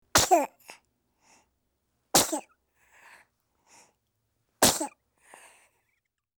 Âm thanh tiếng Hắt xì hơi của trẻ em
Tiếng con người 328 lượt xem 03/03/2026
Tải âm thanh tiếng hắt xì hơi của trẻ em mp3 chân thực nhất.